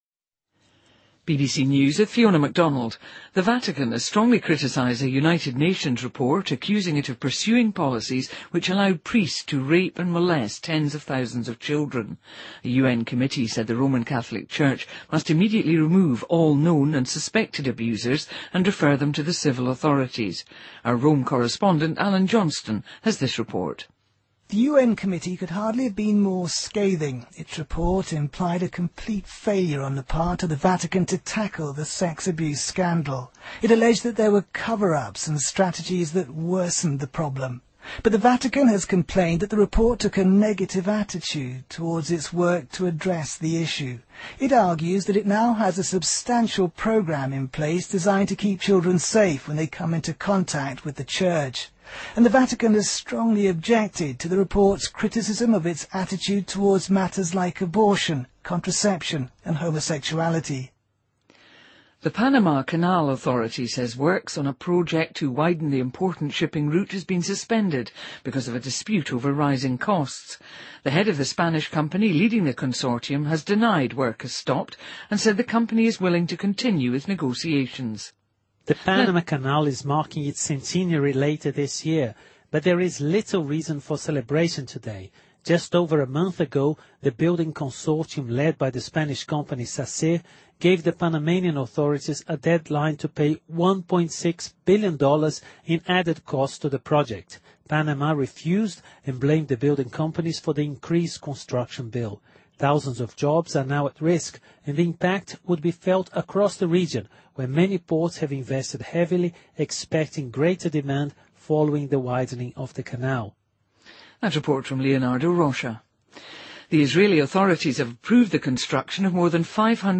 BBC news,2014-02-06